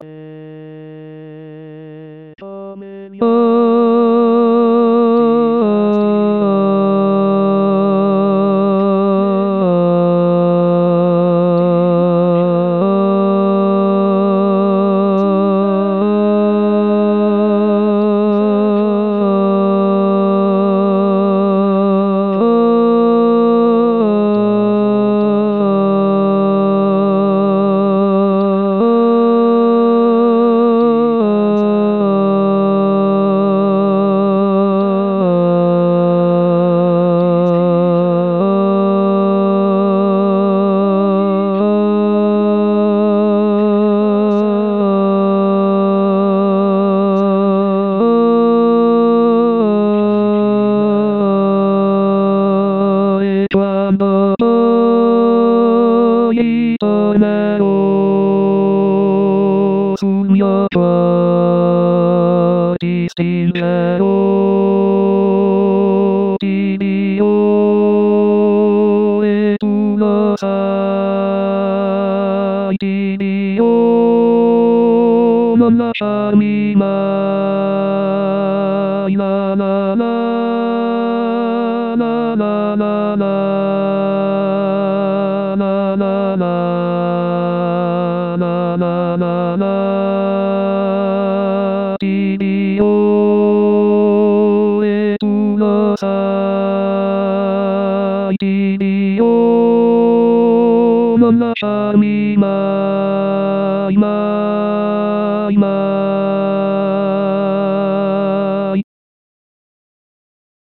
La Ballata del soldato barytons.mp3